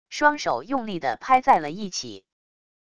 双手用力的拍在了一起wav音频